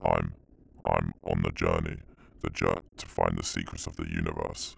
Update Voice Overs for Amplification & Normalisation
I I am on the jounrey the j to find.wav